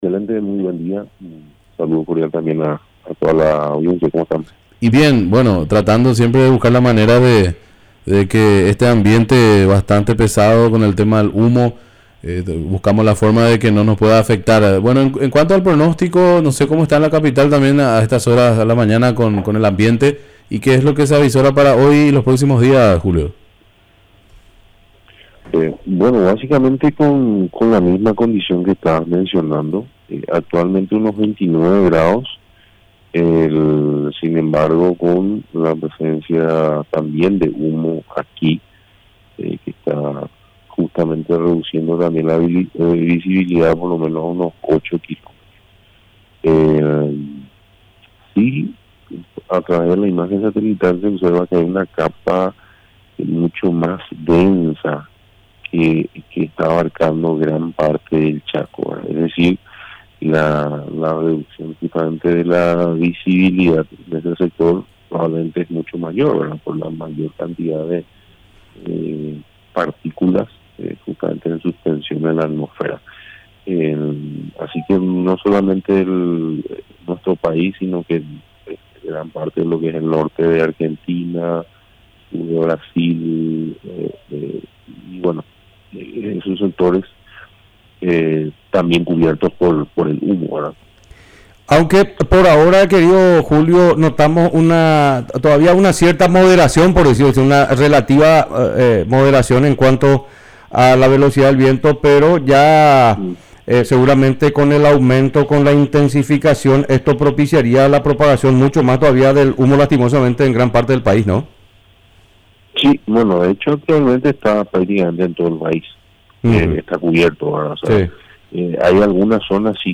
Entrevistas / Matinal 610 Reporte de meteorología Sep 23 2024 | 00:10:09 1x 00:00 / 00:10:09 Subscribe Share RSS Feed Share Link Embed